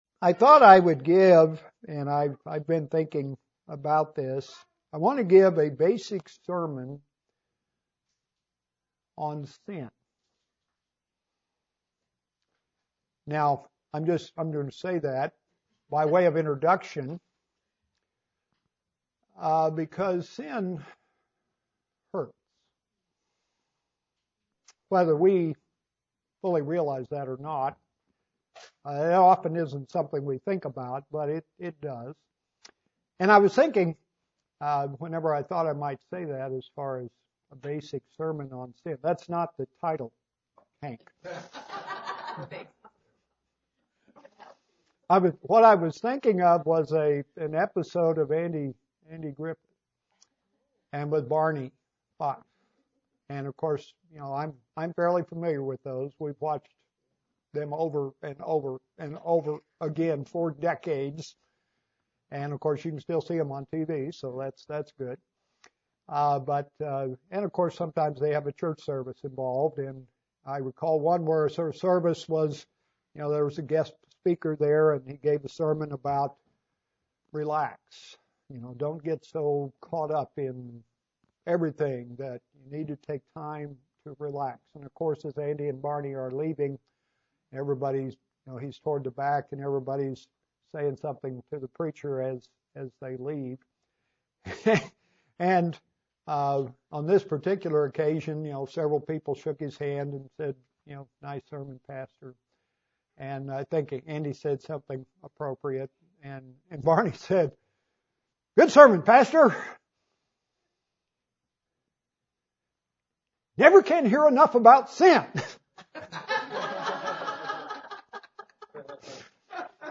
A basic sermon on the subject of sin.